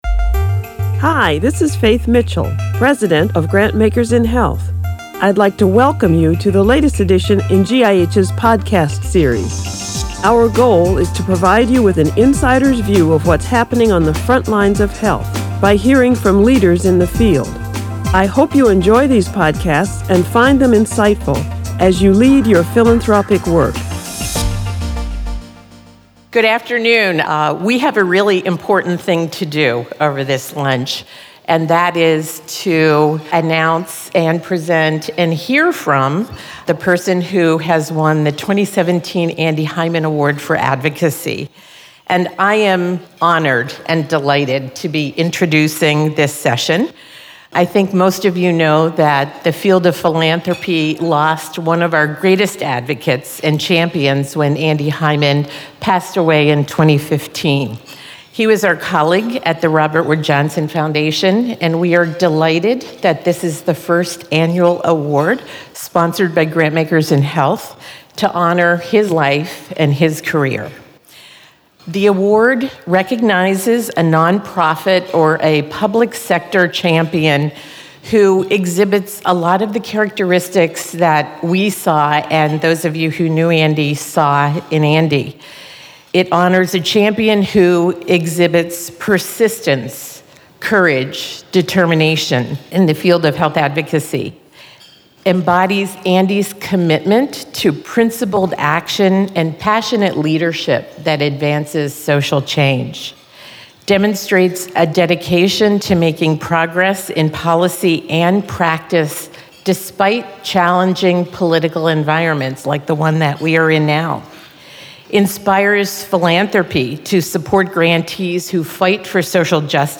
2017 Andy Hyman Award for Advocacy Speech